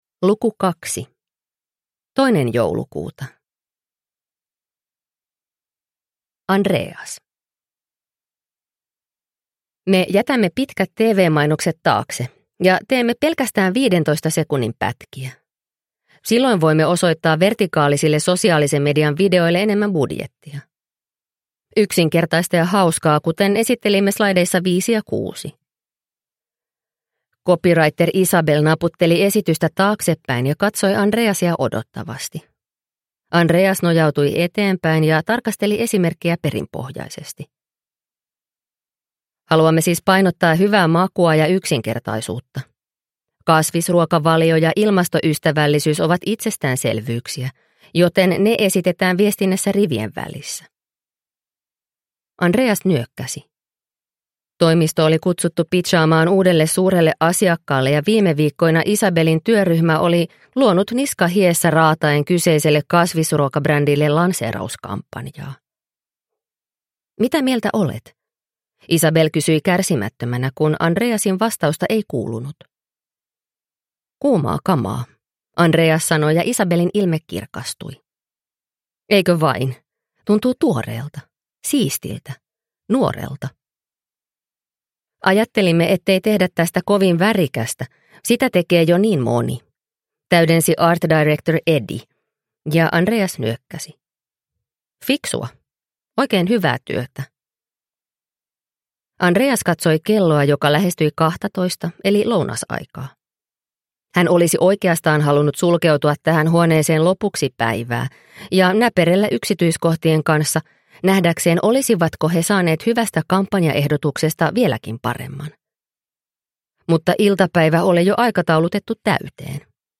Sankt Annan joulu – Ljudbok – Laddas ner
Eleanor Sagerin jouluinen romaani on hurmaavaa kuunneltavaa joulun odotukseen.